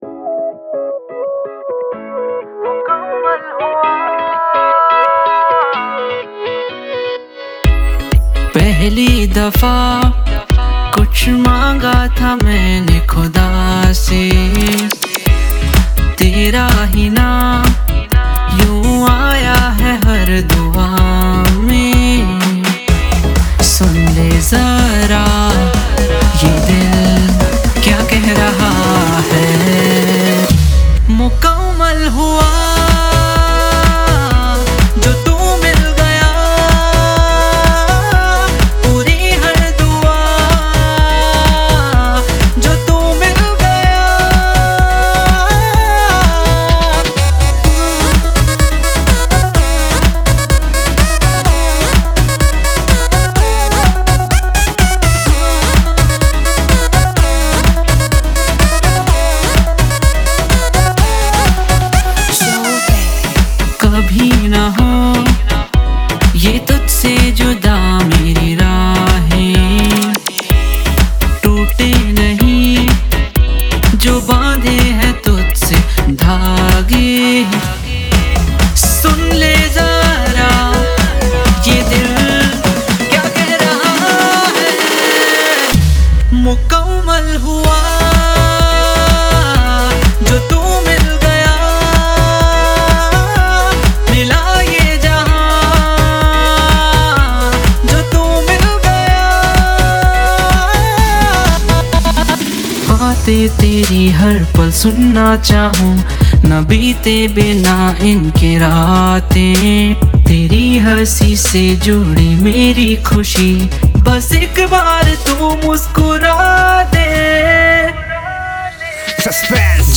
2019 Pop Mp3 Songs